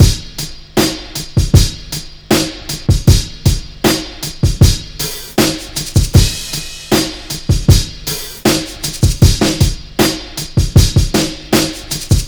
Track 07 - Drum Break 03.wav